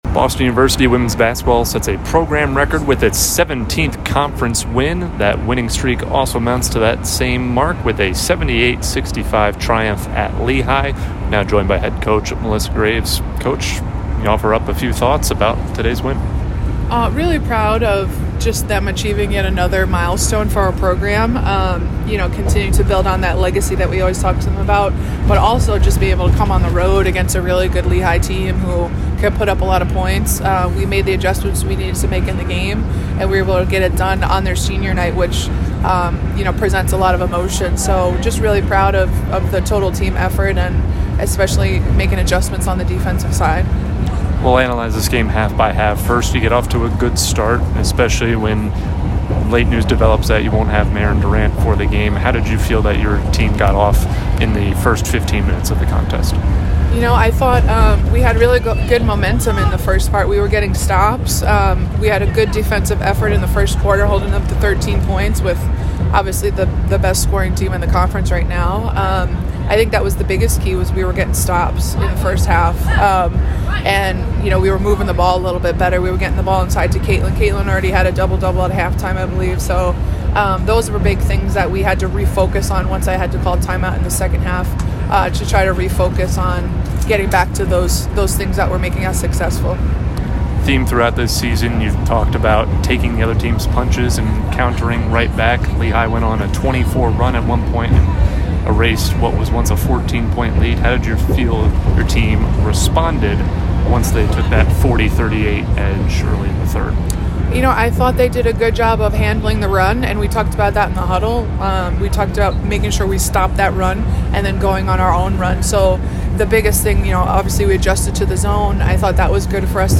Lehigh Postgame Interview
WBB_Lehigh_2_Postgame.mp3